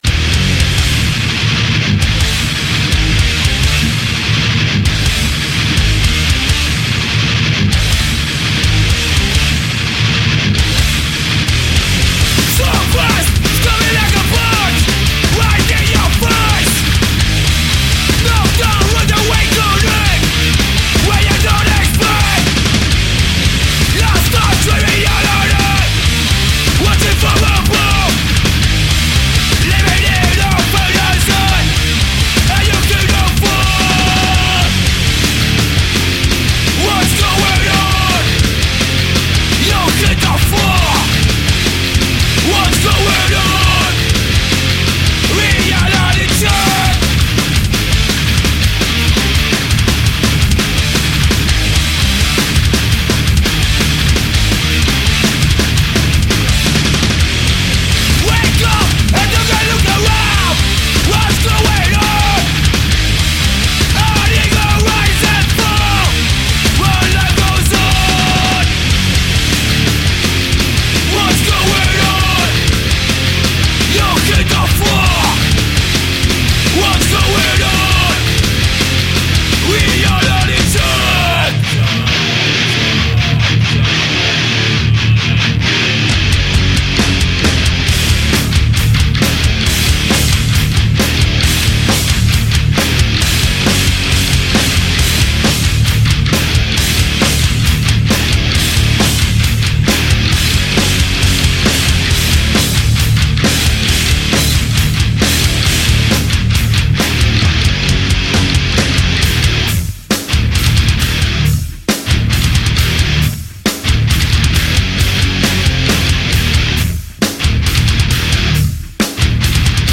punk